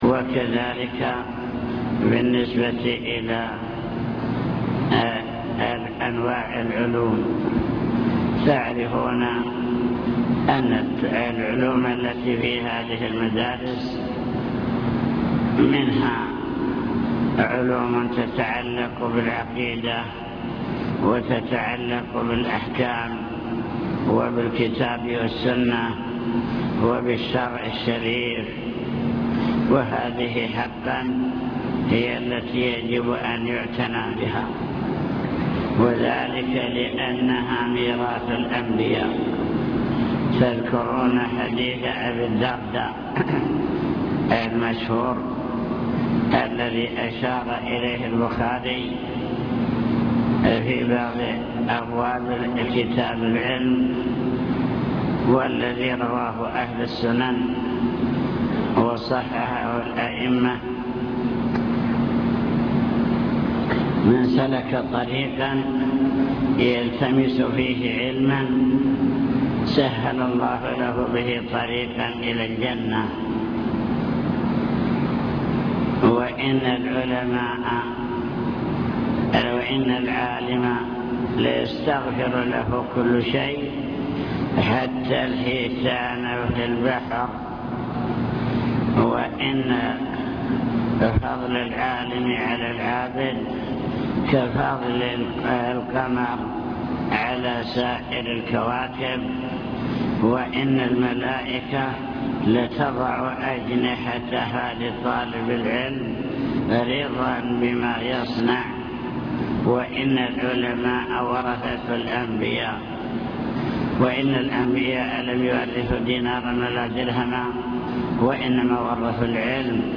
المكتبة الصوتية  تسجيلات - لقاءات  كلمة للمعلمين وطلاب التحفيظ